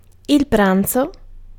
Ääntäminen
IPA : /lʌntʃ/ US